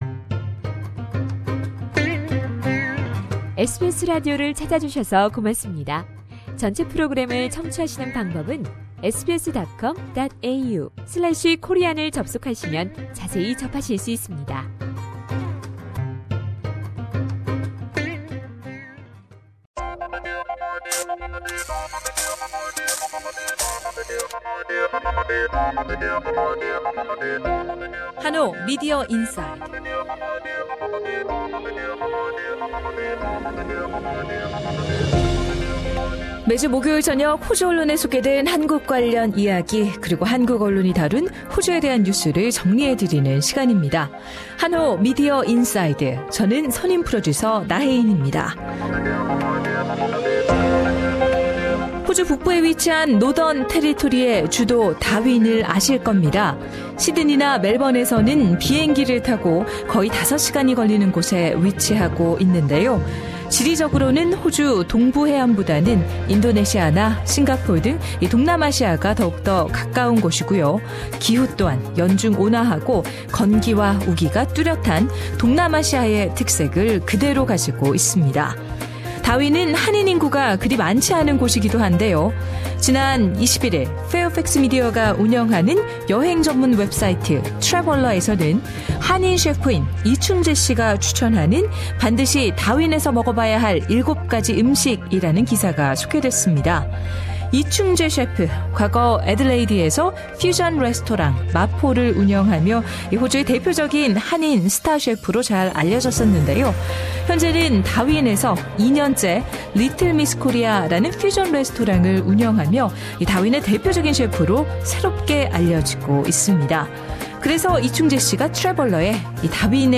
상단의 팟캐스트를 통해 전체 인터뷰를 들으실 수 있습니다.